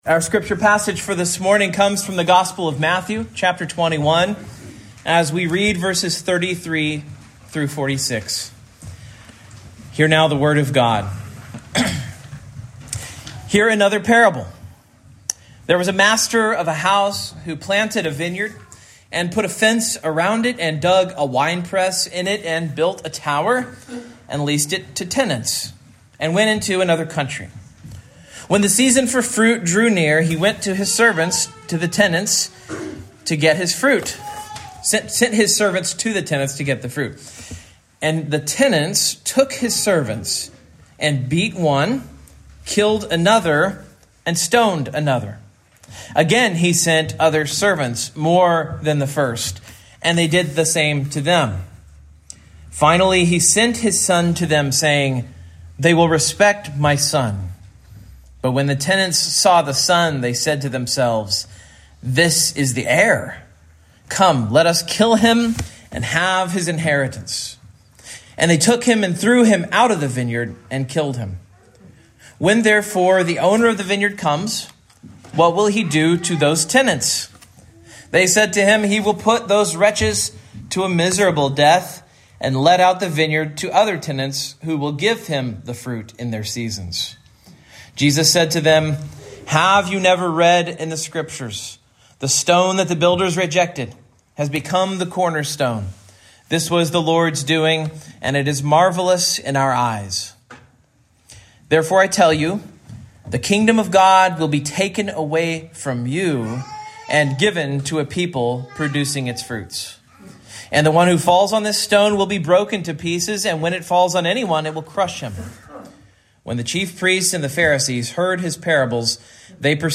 Matthew 21:33-46 Service Type: Morning Main Point